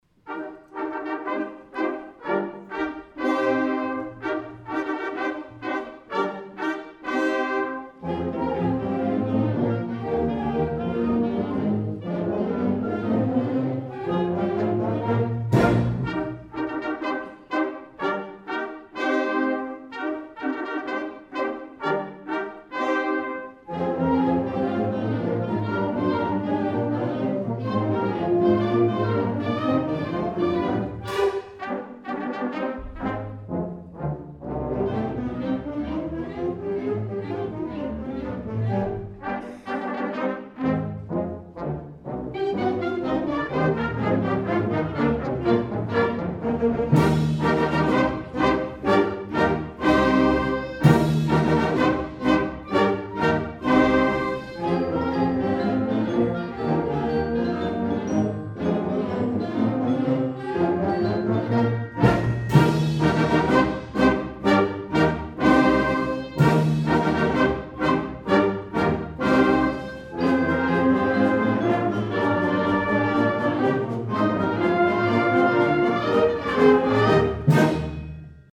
Below are music excerpts from some of our concerts.
2009 Winter Concert
December 20, 2009 - San Marcos High School